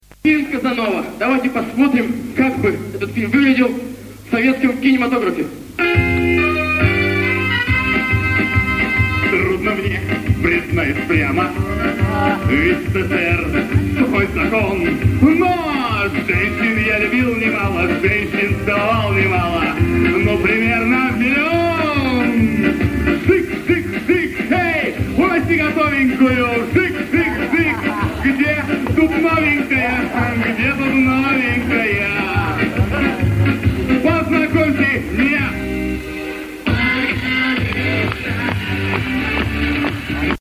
1/2 финала 2005-2006 г. Лиги Университетов, Томск